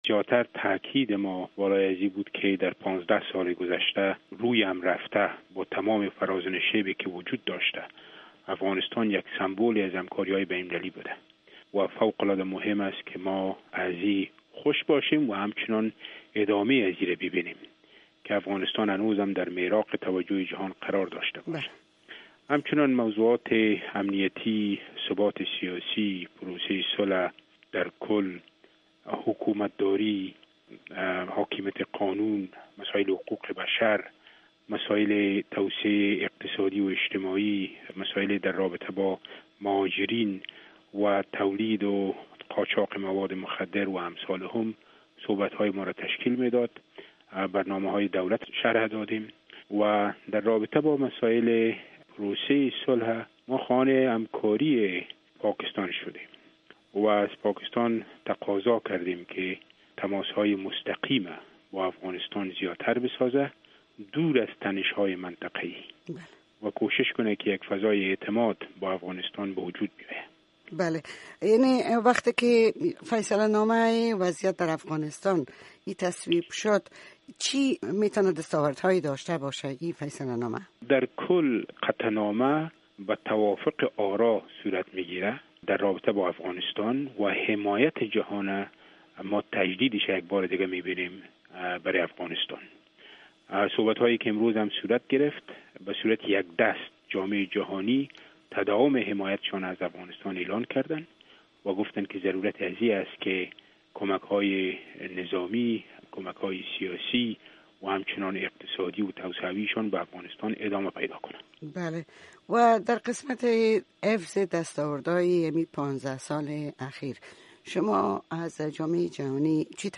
مصاحبه با محمود صیقل، نماینده دایمی افغانستان در سازمان ملل متحد